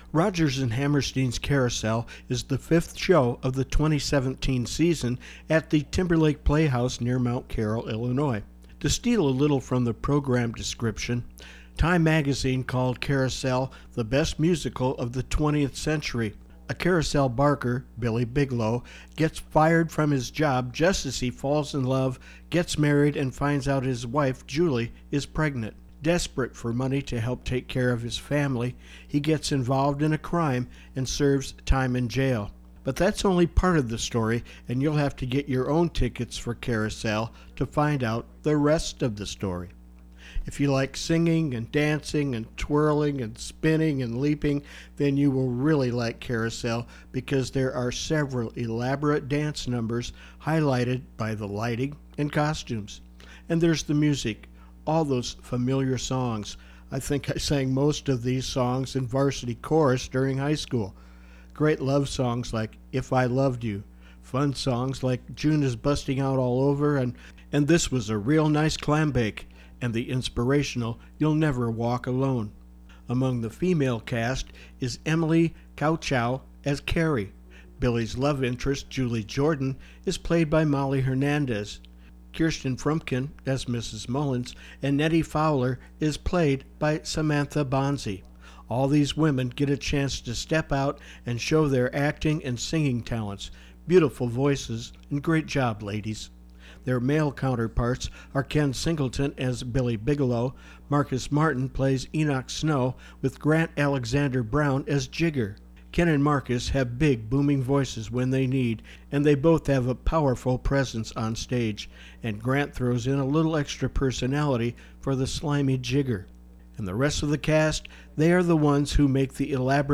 Carousel-Review.wav